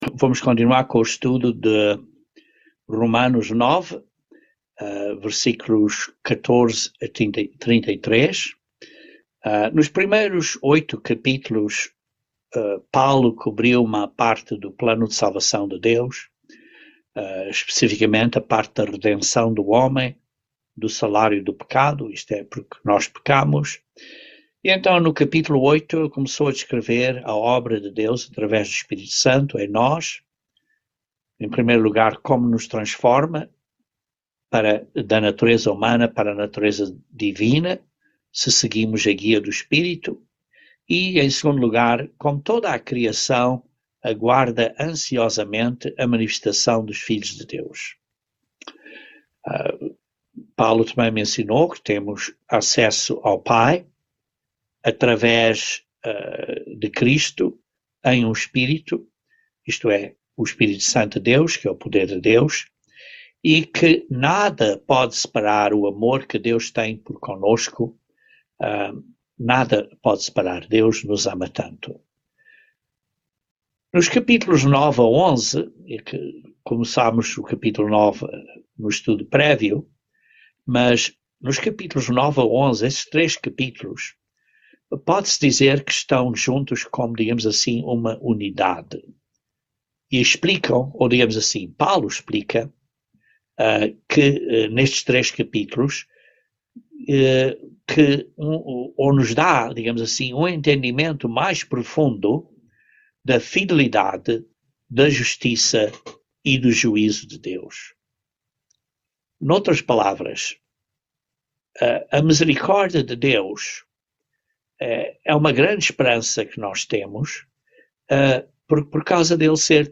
Romanos 9:14-33 - Estudo Bíblico